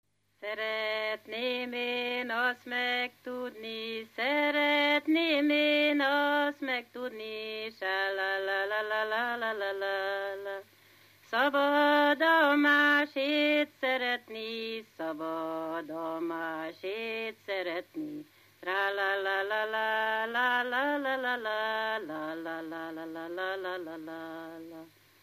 Erdély - Alsó-Fehér vm. - Magyarbece
ének
Műfaj: Lassú csárdás
Stílus: 3. Pszalmodizáló stílusú dallamok